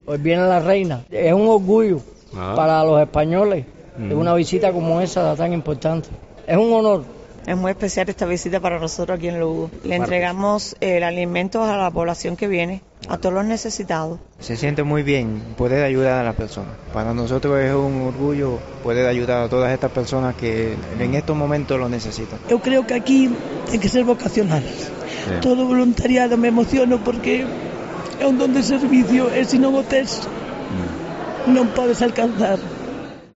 Voluntarios del Banco de Alimentos hablan de la visita de la reina Sofía en el micro de COPE Lugo